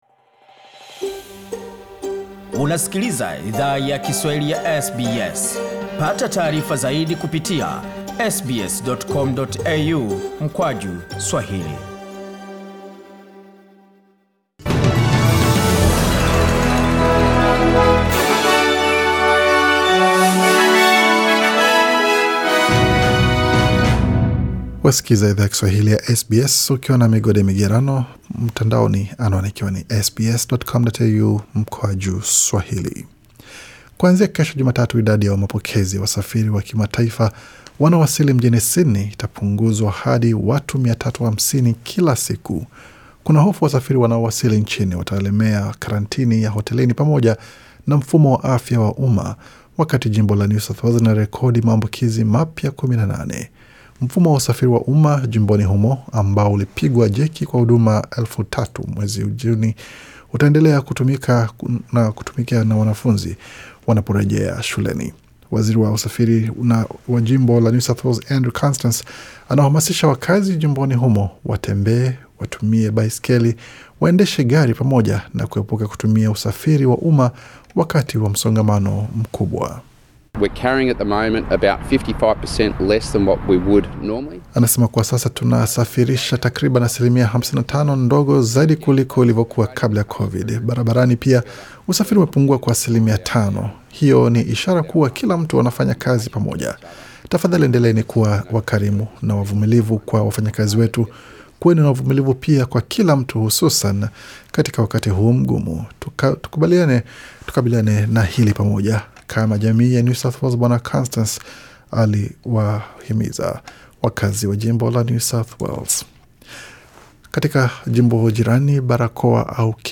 Taarifa ya habari 19 Julai 2020